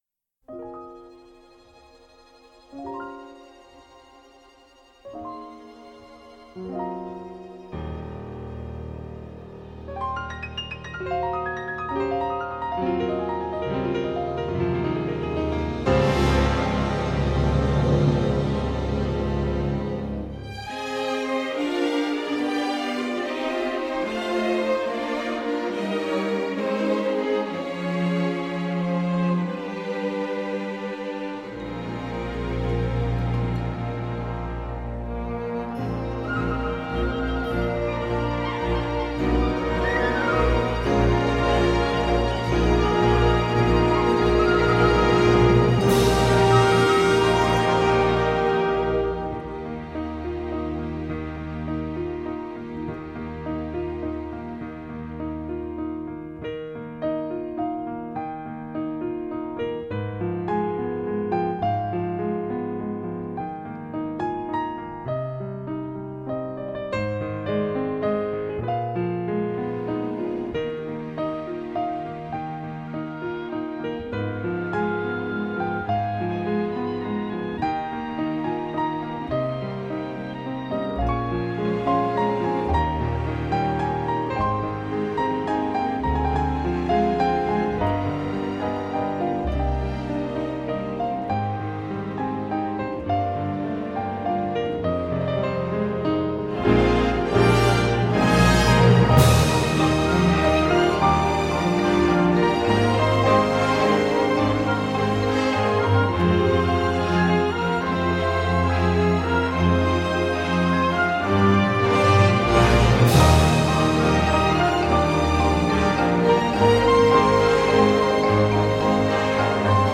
24BIT/192K DSP MASTERING